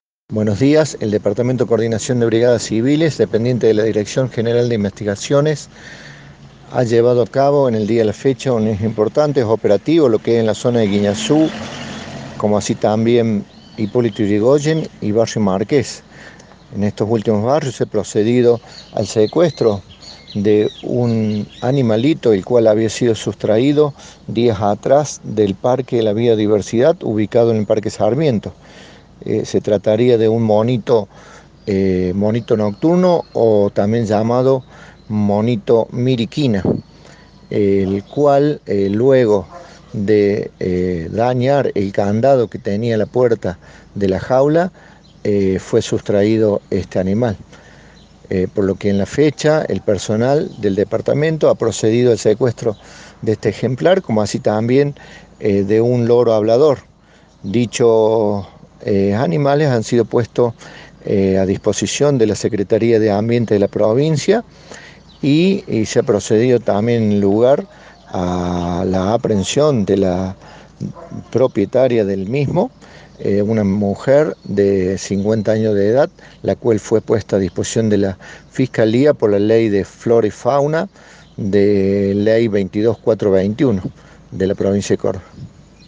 Audio: Crio. Insp.